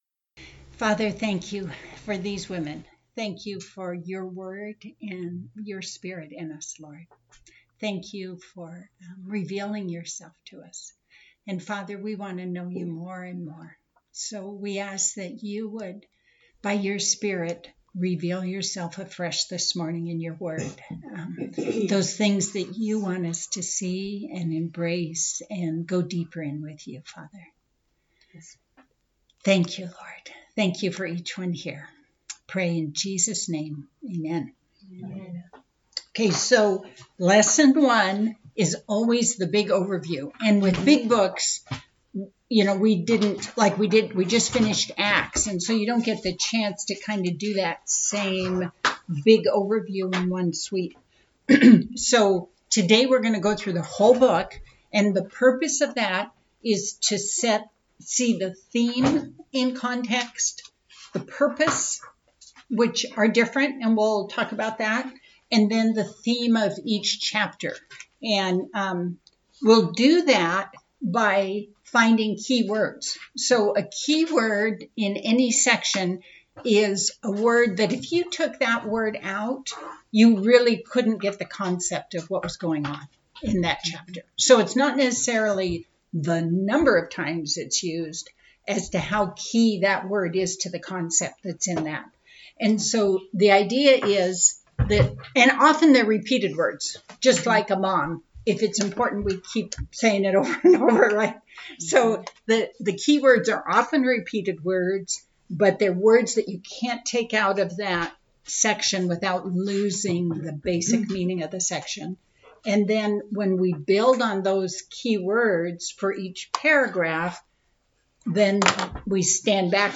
A message from the series "James."